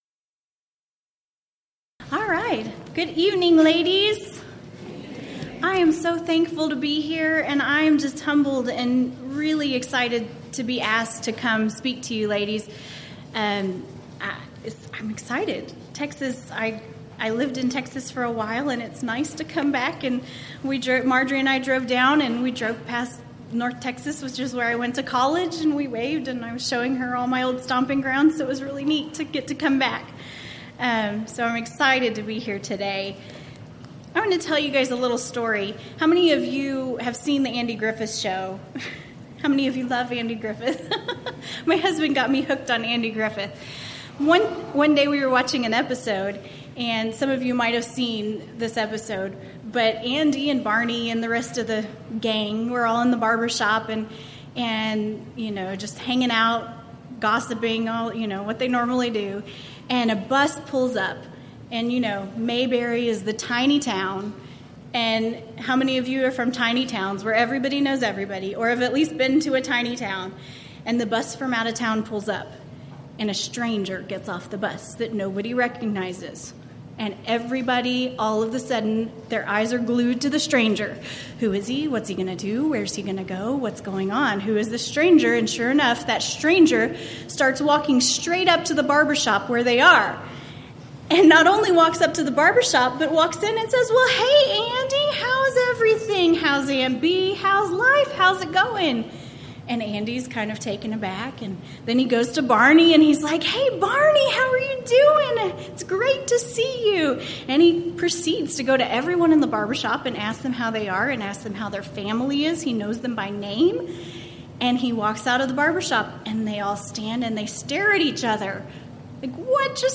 Event: 2014 Texas Ladies in Christ Retreat Theme/Title: Colossians & Philemon
Ladies Sessions